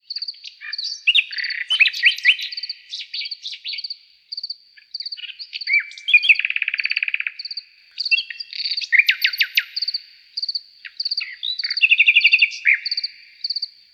Звуки на звонок